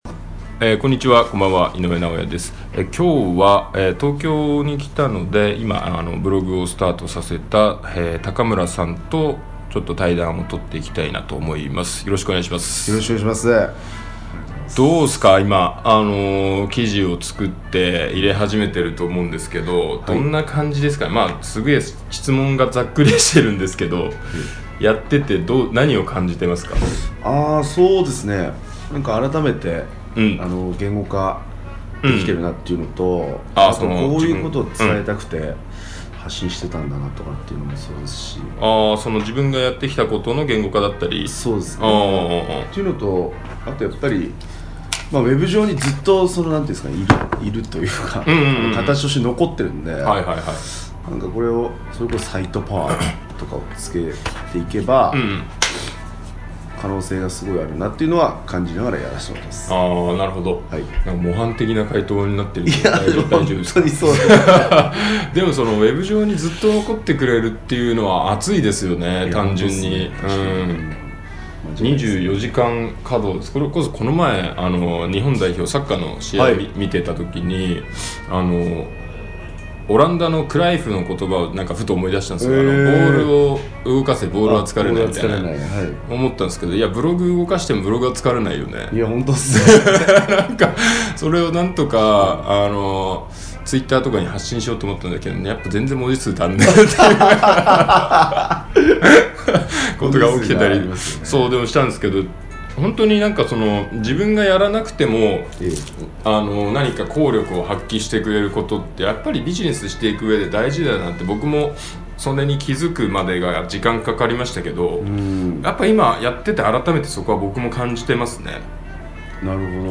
ということで、本日は番外編で対談音声をお届けします。